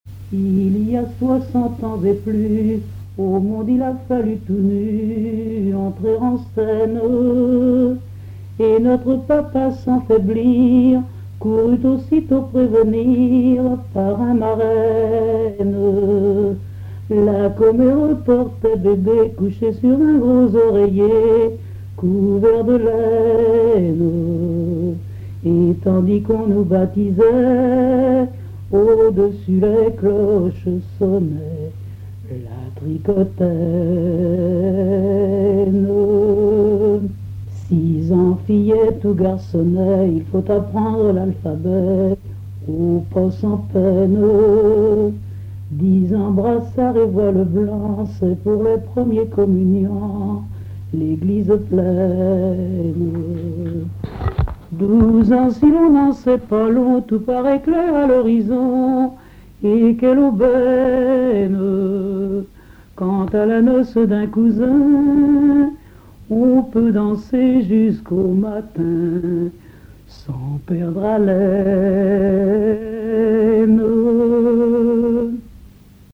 Récits et chansons en patois
Catégorie Pièce musicale inédite